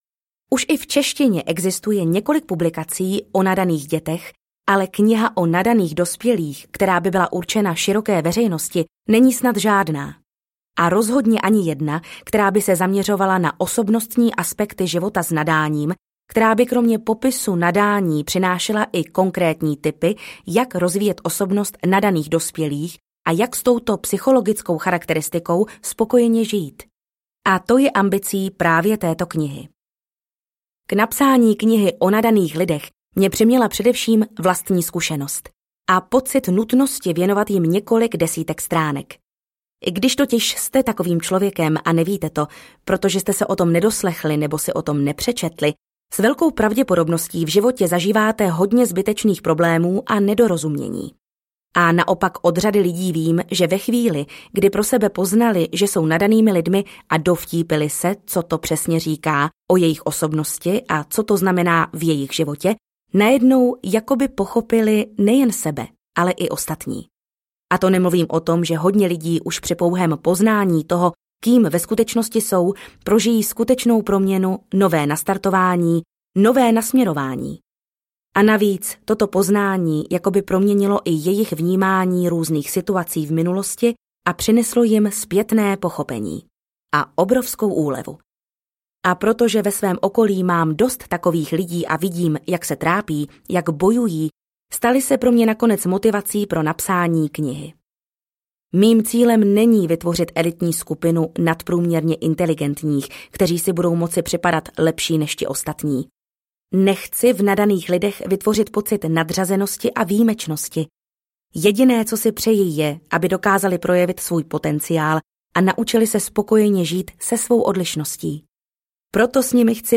Život s vysokou inteligencí audiokniha
Ukázka z knihy